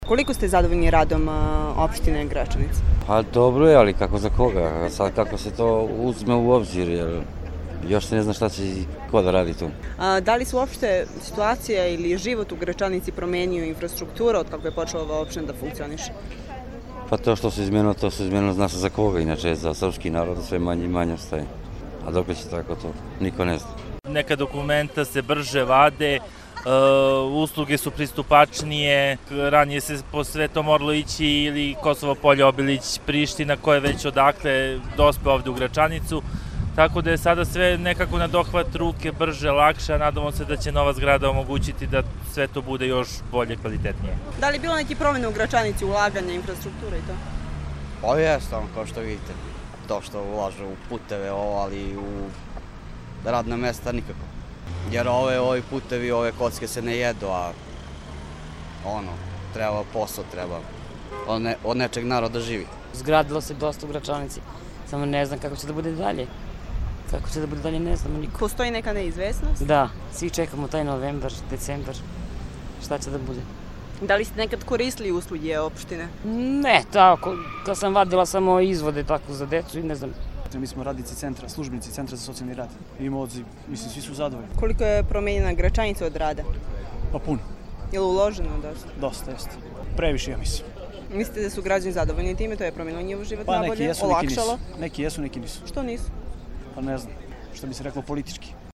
Građani o životu u Gračanici